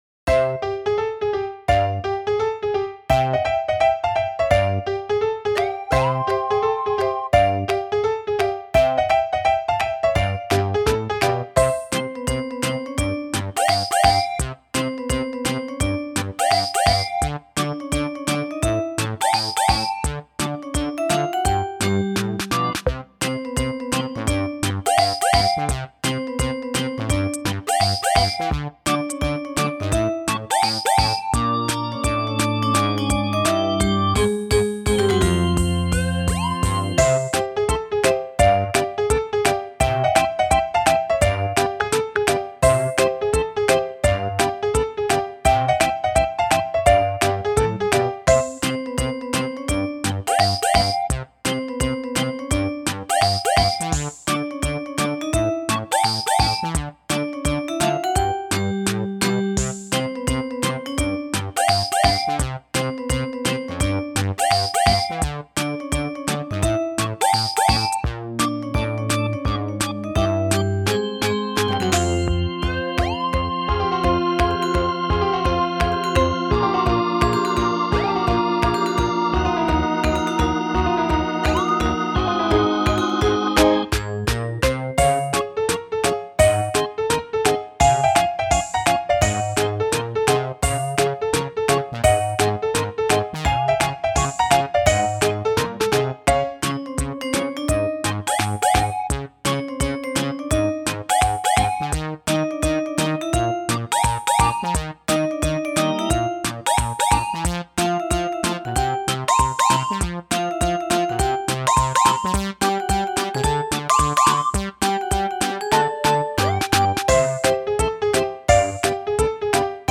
Весёлая музыка для детского и взрослого видео